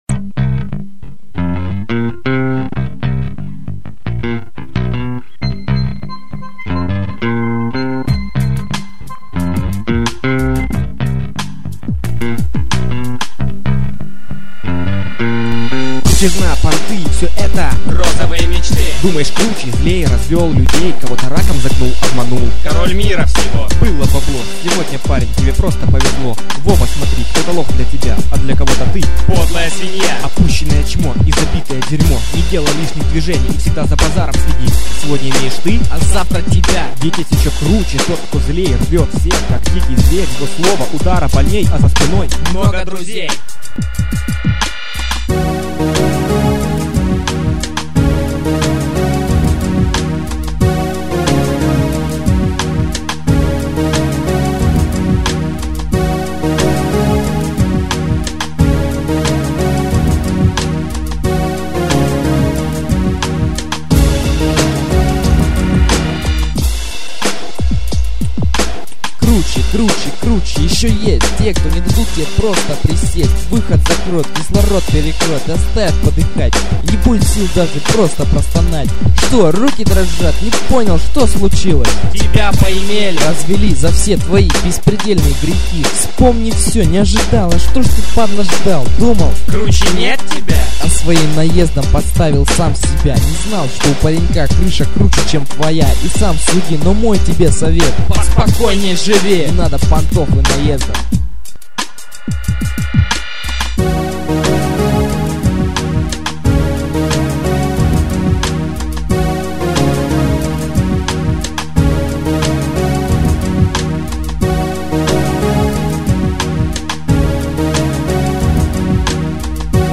реп группы